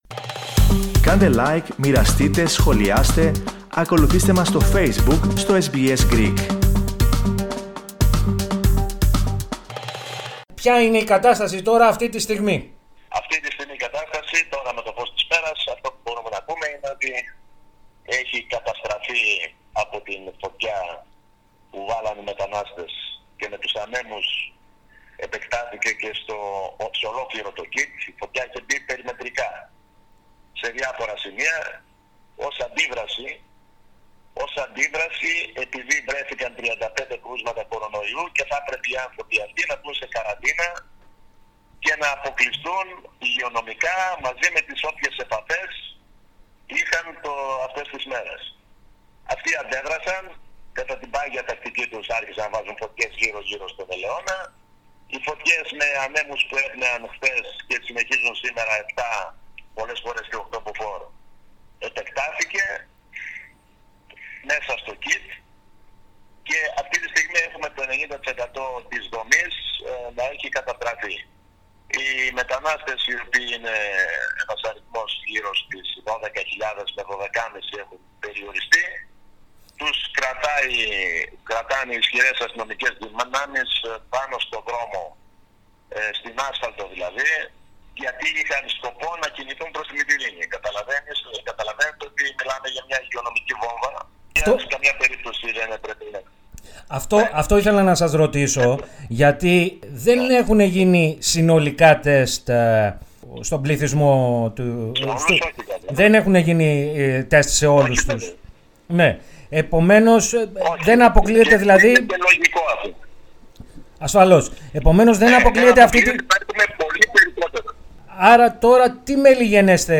Σε συνέντευξη που παραχώρησε στο Ελληνικό Πρόγραμμα της ραδιοφωνίας SBS, ο αντιπεριφερειάρχης Βορείου Αιγαίου, Άρης Χατζηκομνηνός, σημείωσε ότι το 90% του ΚΥΤ της Μόριας έχει καταστραφεί.